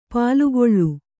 ♪ pālugoḷḷu